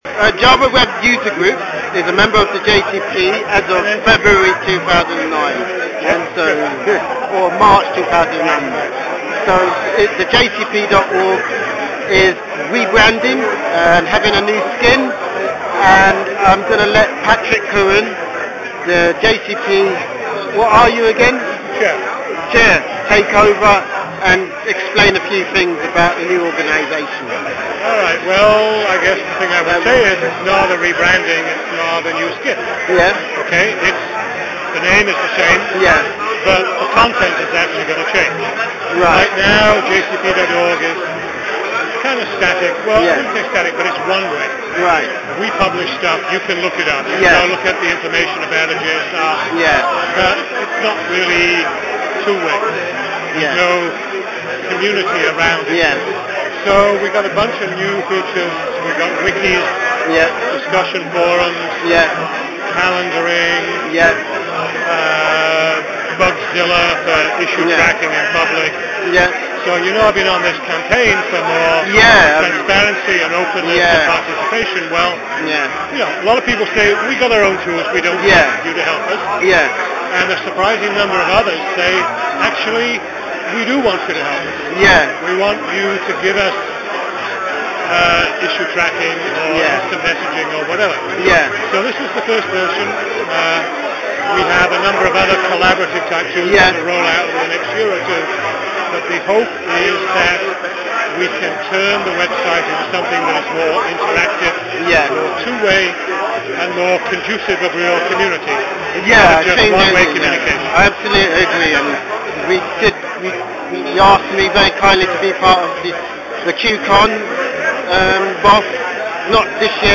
JavaOne: 2009 JCP Party: Interview